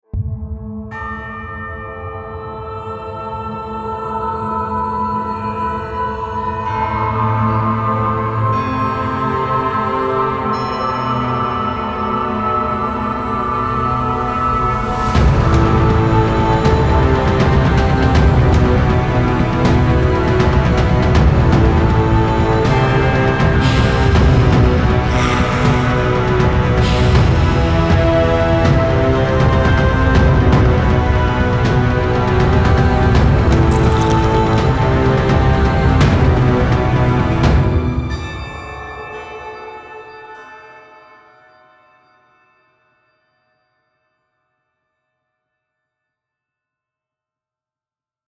medieval-horror-music-theme-2916.mp3